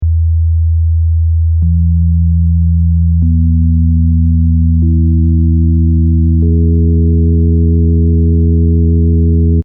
Here’s that same pure 82 Hz tone, with the harmonics 2x, 3x, 4x and 5x added successively:
Here, in contrast, is the same demonstration but with the harmonics detuned randomly by less than two percent: